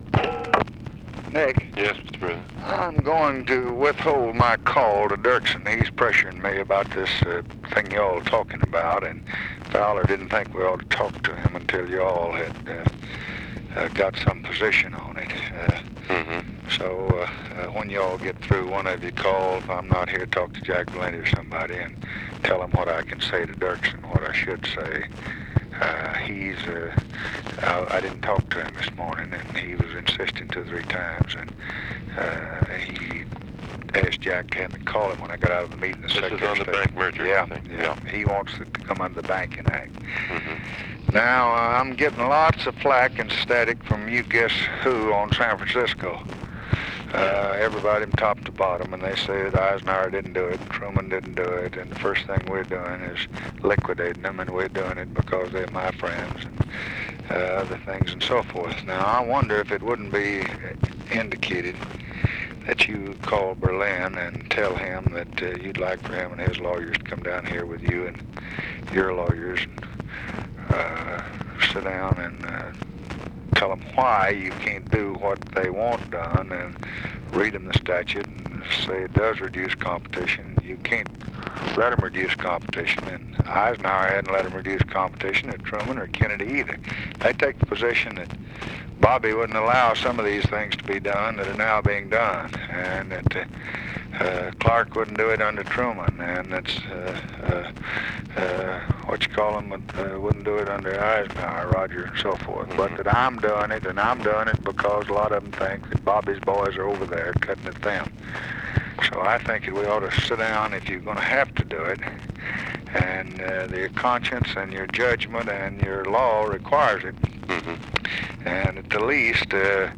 Conversation with NICHOLAS KATZENBACH, May 25, 1965
Secret White House Tapes